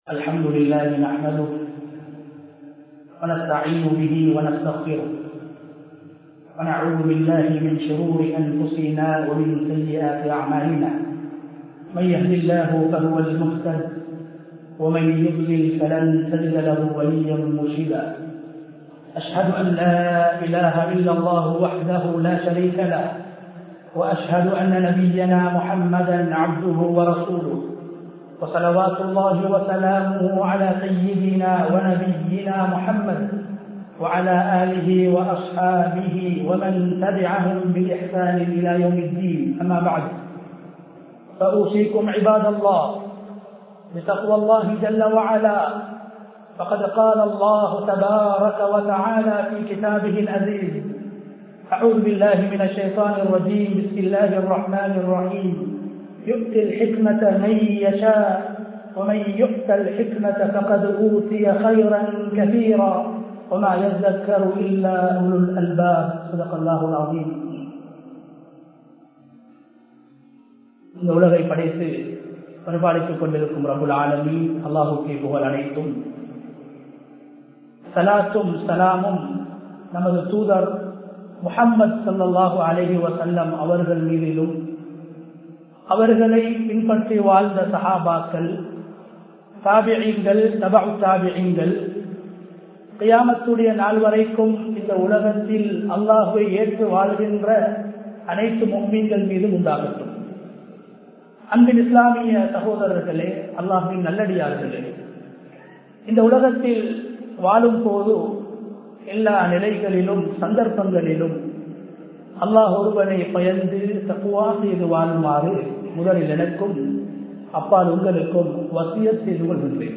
மனிதர்களின் தவறுகளை நபியவர்கள் திருத்திய முறைகள் | Audio Bayans | All Ceylon Muslim Youth Community | Addalaichenai
Colombo 03, Kollupitty Jumua Masjith 2022-10-28 Tamil Download